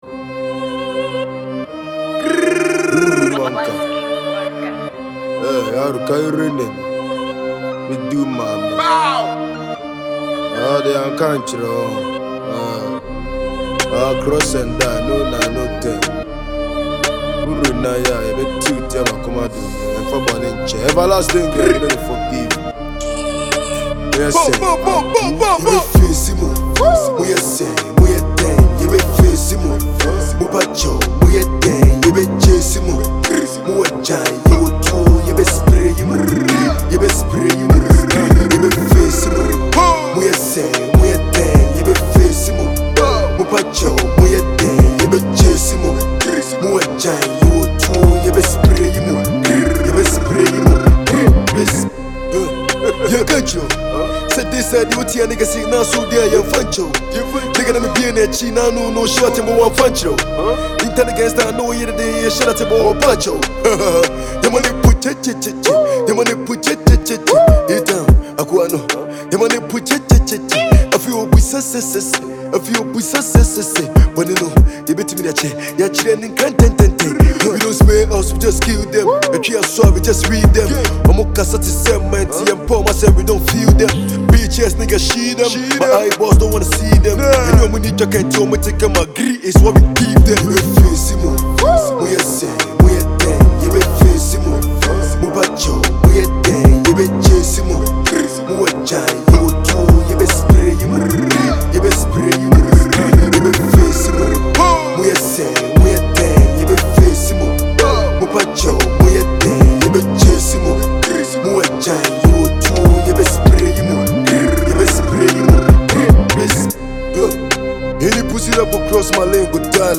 Ghanaian drill sensation
Asakaa anthem